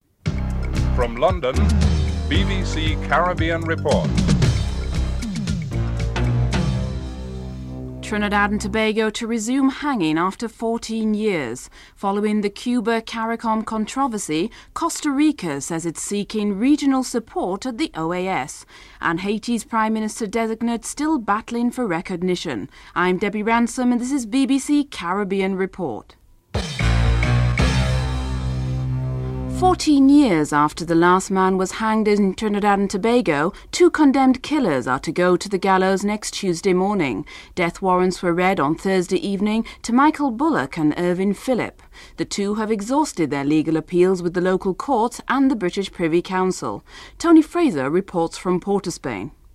1. Headlines (00:00-00:27)
Interview with Eugenia Charles, Prime Minister of Dominica (08:23-12:16)